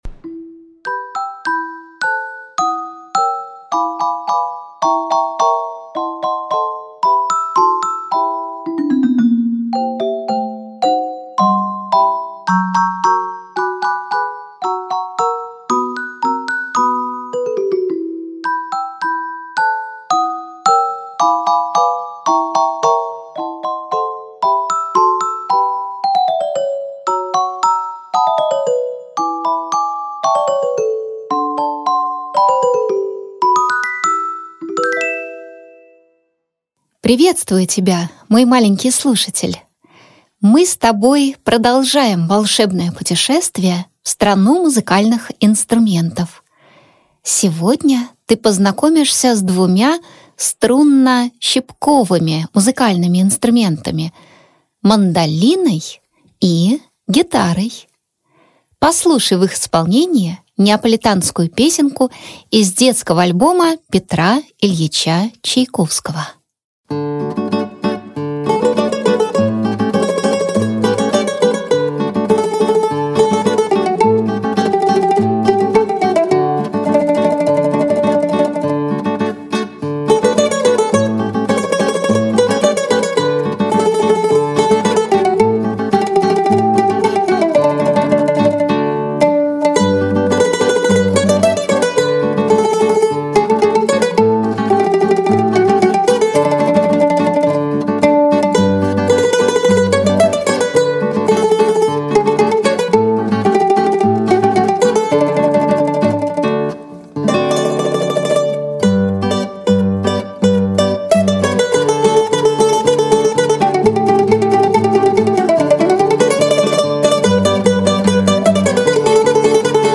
Аудиокнига Мандолина и гитара. Путешествие по Италии | Библиотека аудиокниг